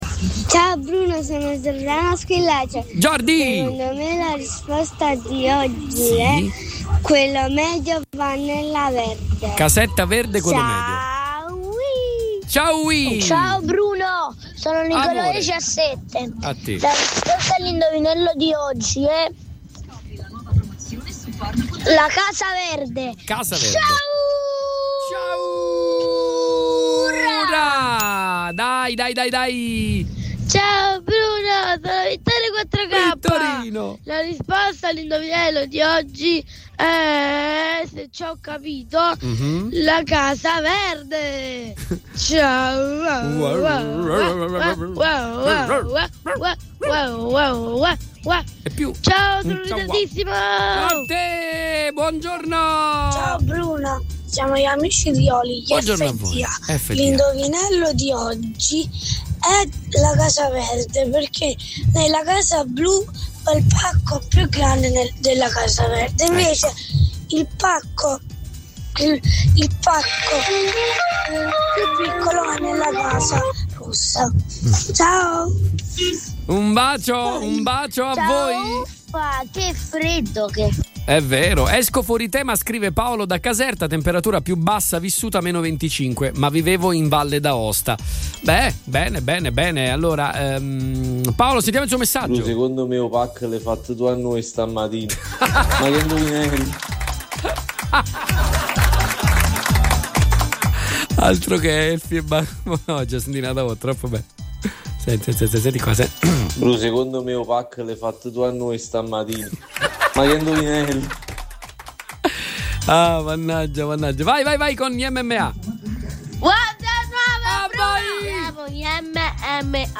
AUDIO – LE RISPOSTE DEGLI ASCOLTATORI